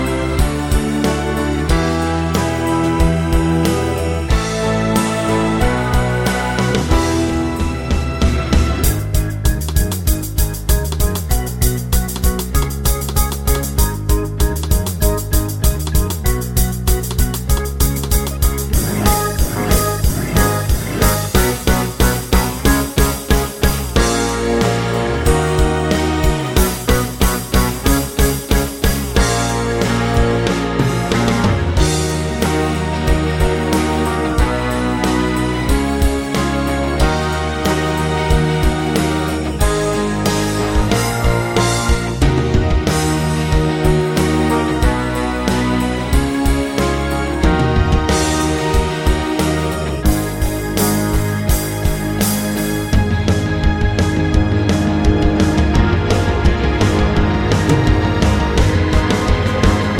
TV Length With No Backing Vocals Soundtracks 1:58 Buy £1.50